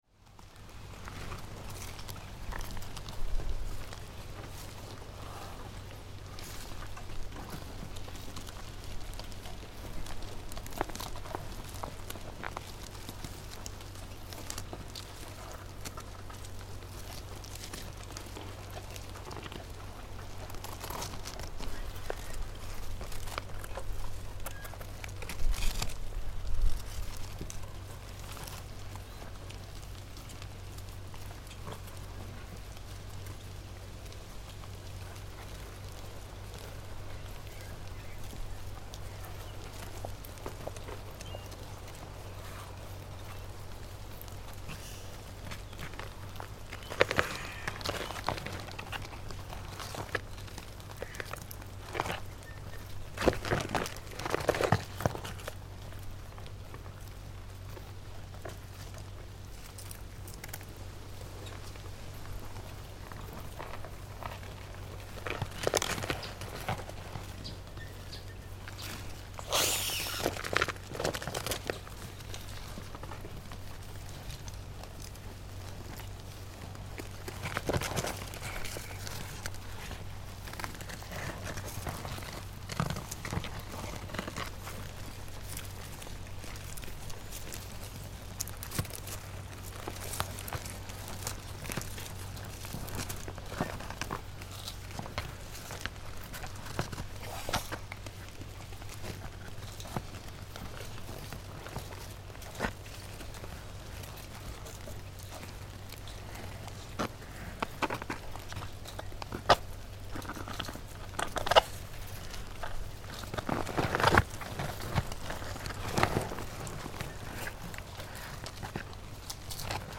Черепахи шагают по гравию слышны звуки шагов